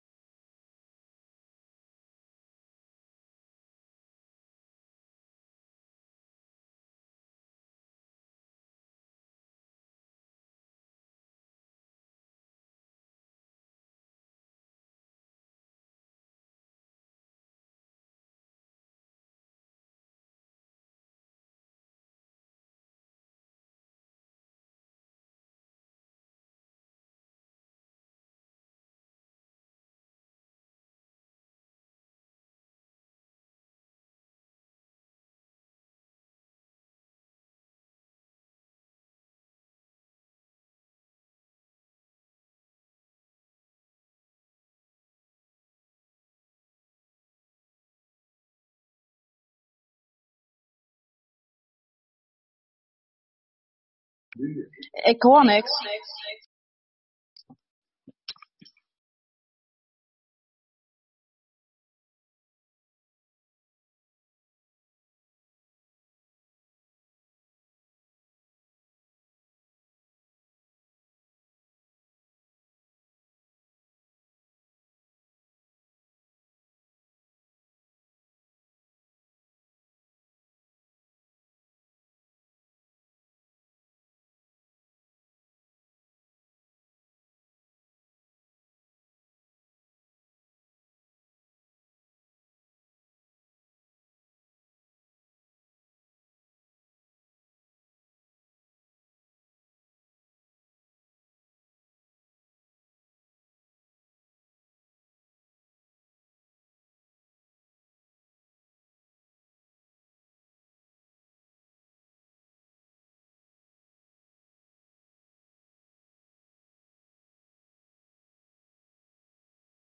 De raadsvergadering is live uitgezonden.